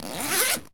foley_zip_zipper_long_04.wav